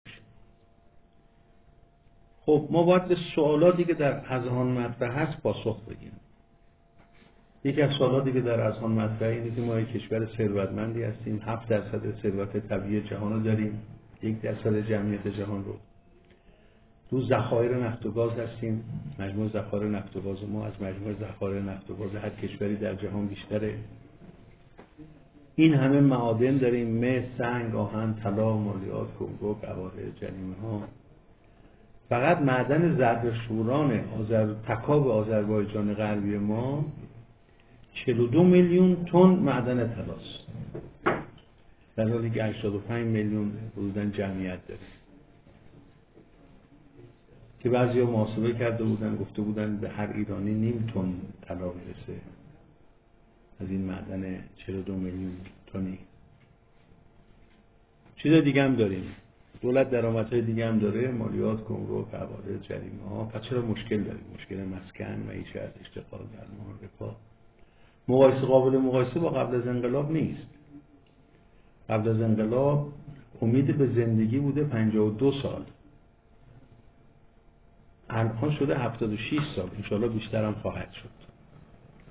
نشست تبیینی دستاوردهای اقتصادی و اقتصاد اسلامی در ایران از سوی کانون قرآن و عترت دانشگاه علامه با حضور قدیری‌ابیانه برگزار شد.